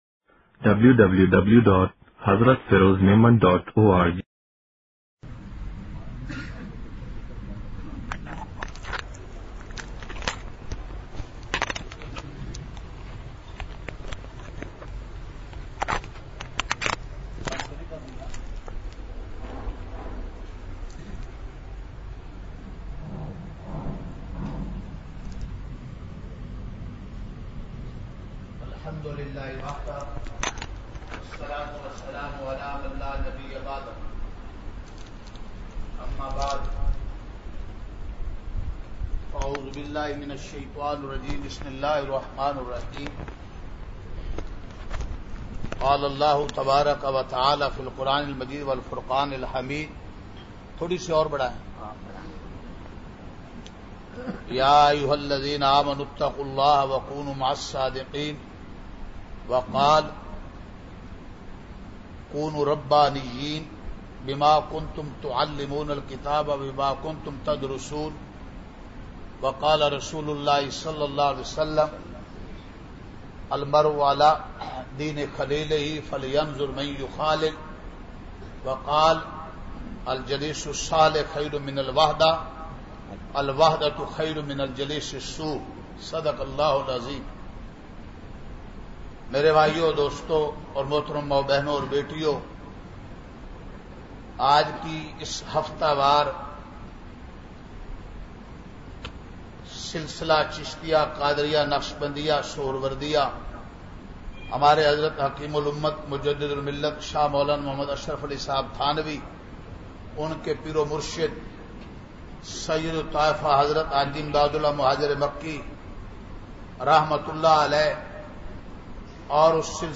مجلس ۰۸ اگست ۲۰۱۸ء : بے حیائی ،عریانی اور فحاشی کے طوفان سے کیسے بچا جائے (سفر لاہور) !